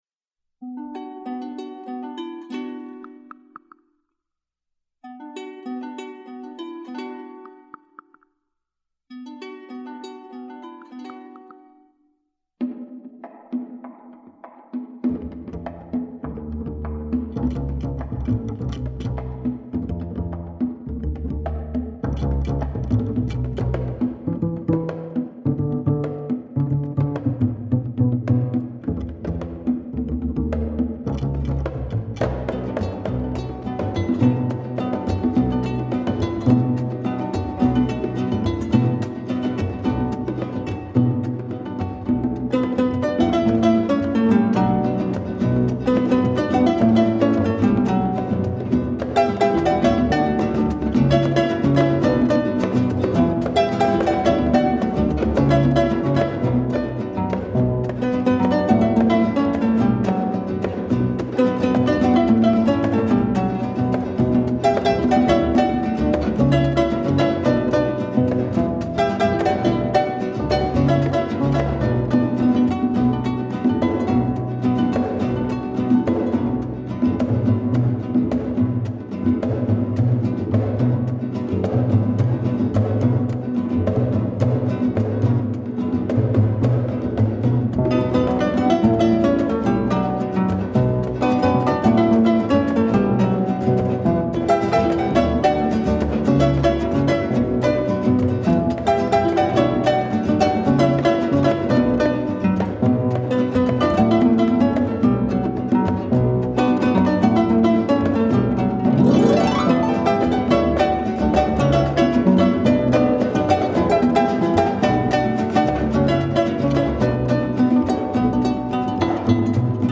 smooth sounds of the Guarani Indians on the Paraguyan Harp